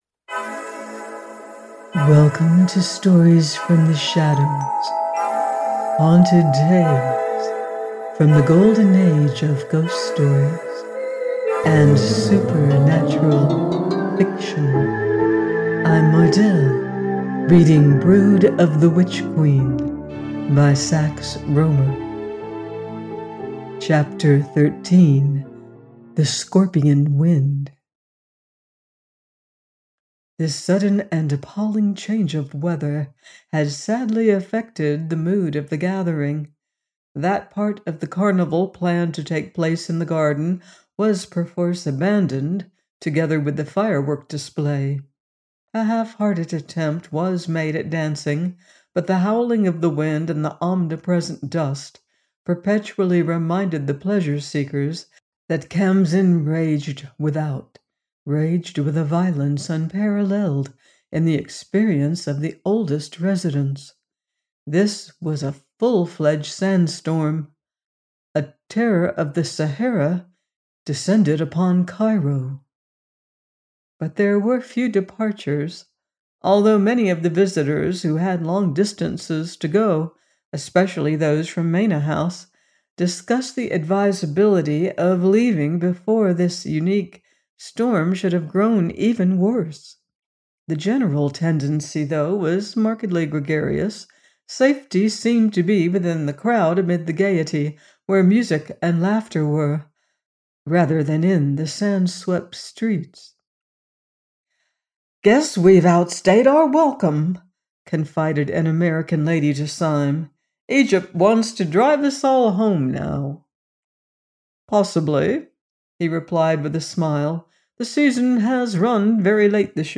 Brood of the Witch Queen – 13 : by Sax Rohmer - AUDIOBOOK